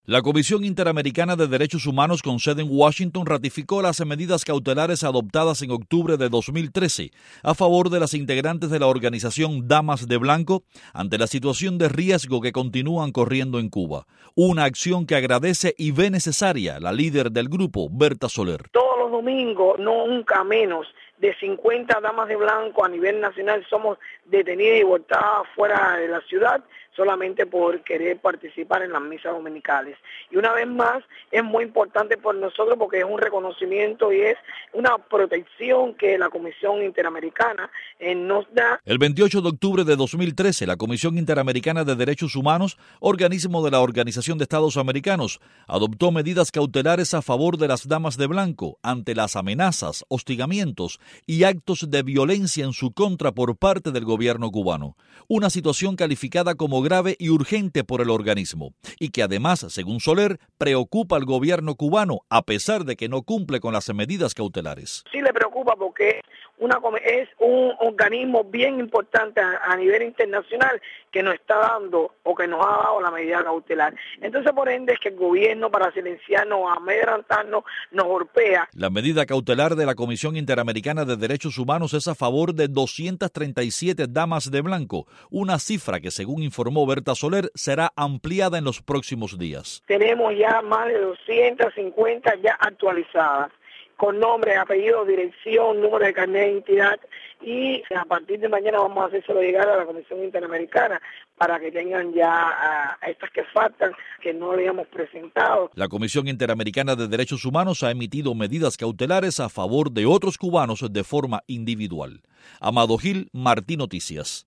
conversó con la líder de la organización